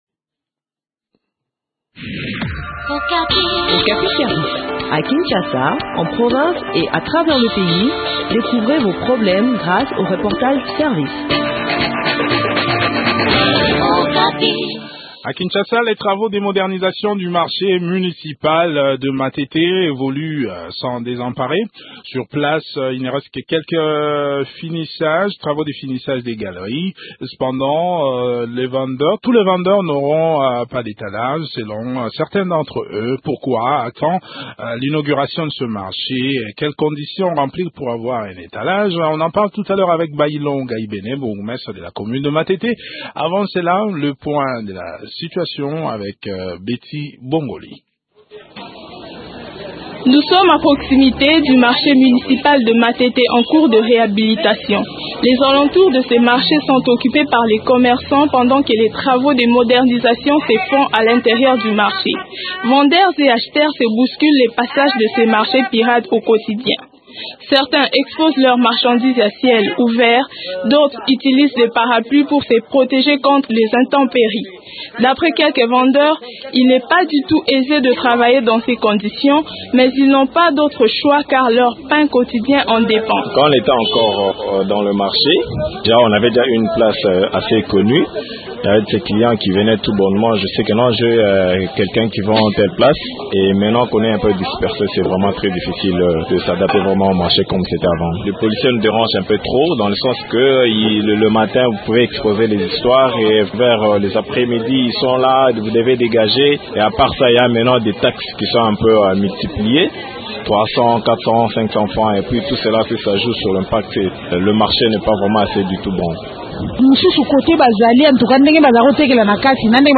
Bâillon Gaibene, bourgmestre de la commune de Matete fait le point sur les dispositions prises pour la distribution des étalages.